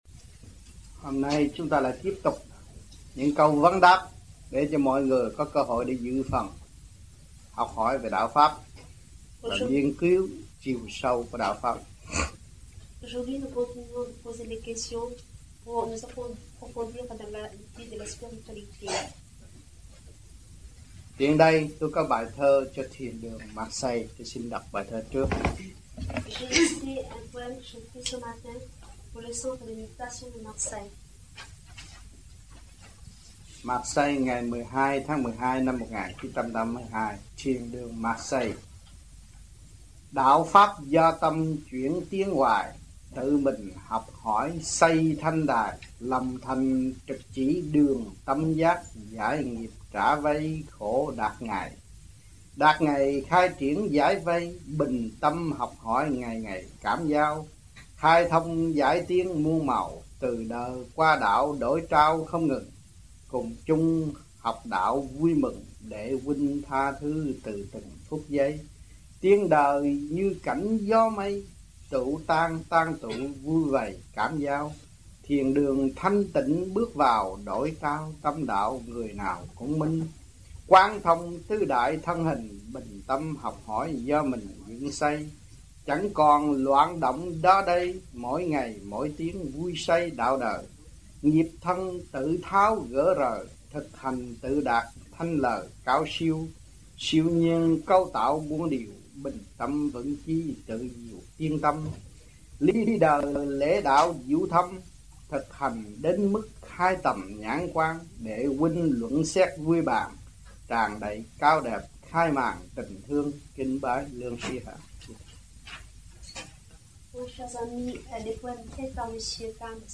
1982-12-11 - MARSEILLE - THUYẾT PHÁP 04